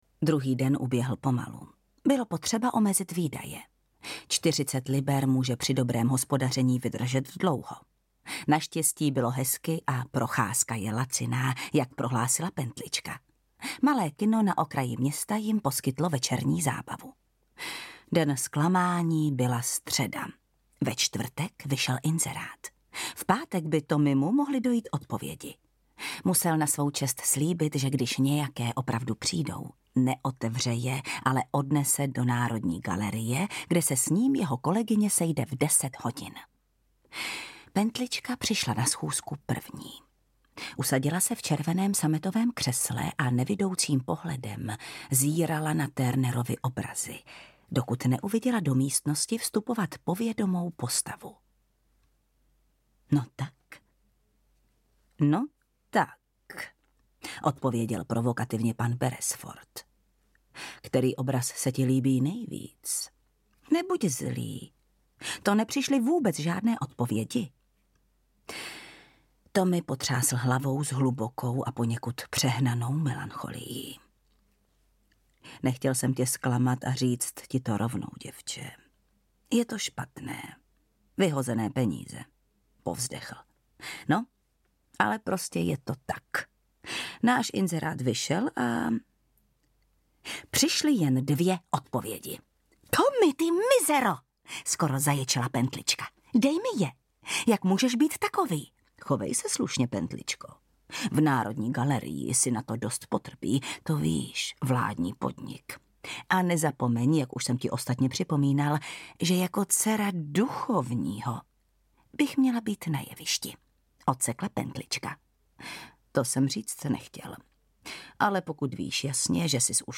Tajemný protivník audiokniha
Ukázka z knihy
• InterpretJana Stryková